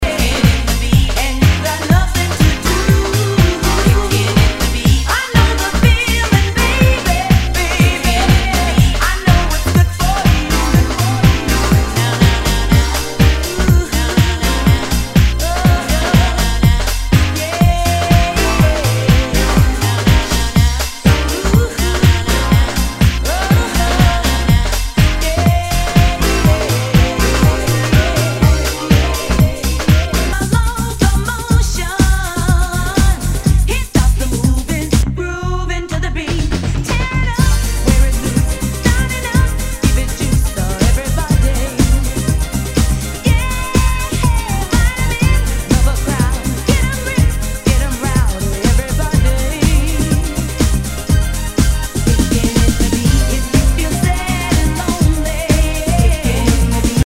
HOUSE/TECHNO/ELECTRO
ヴォーカル・ハウス・クラシック！